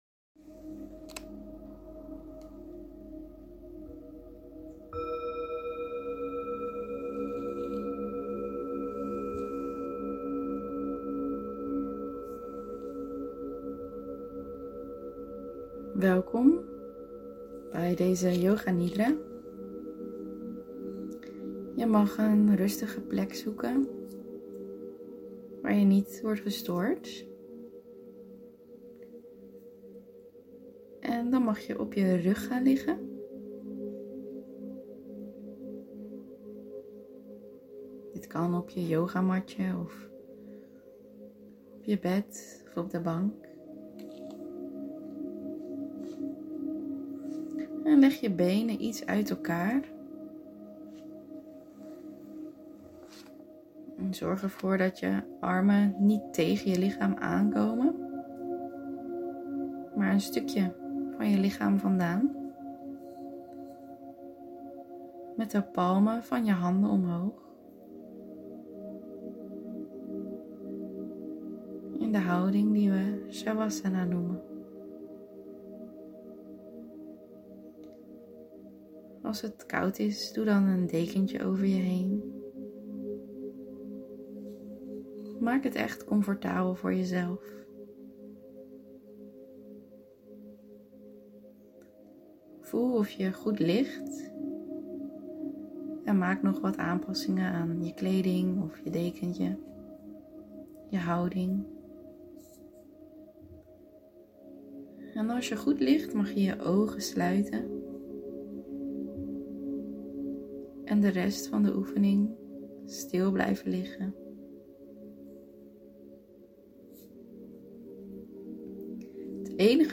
Yoga_nidra_berg_visualisatie.mp3
En je volgt mijn stem.